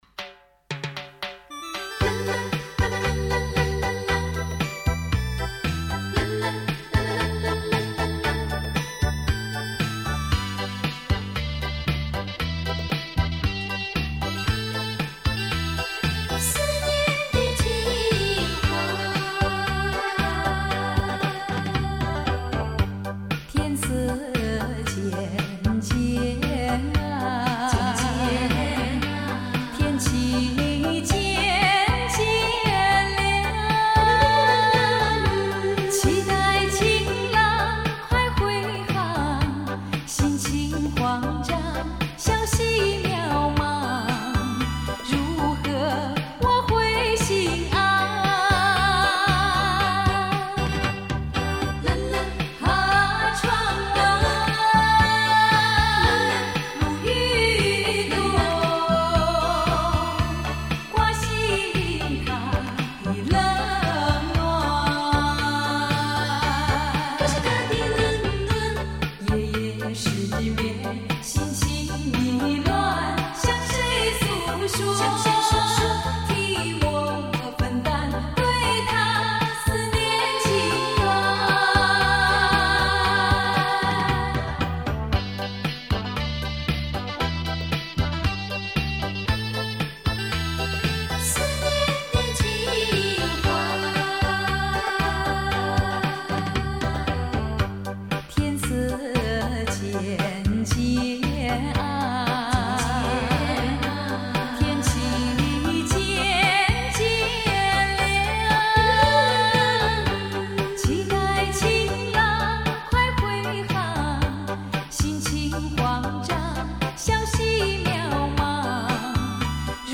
一组曾经风靡于台湾各色小酒吧的情歌恋曲